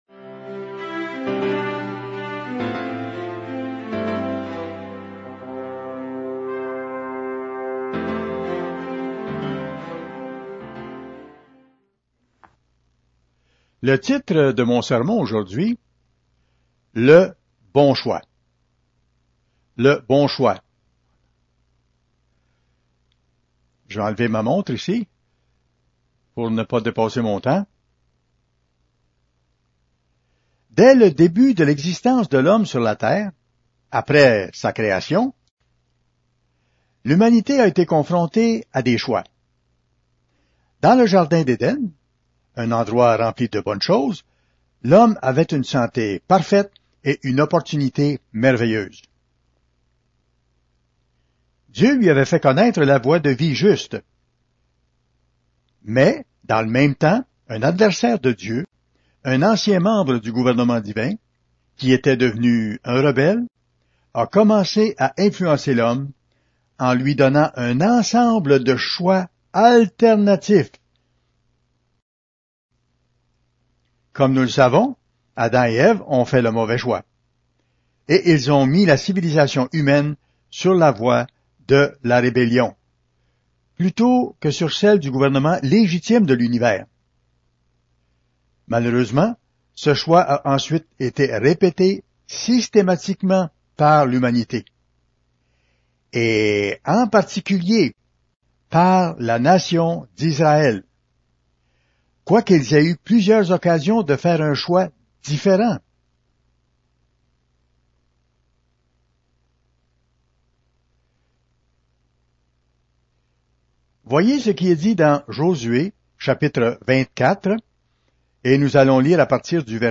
Fête des Tabernacles – 1er jour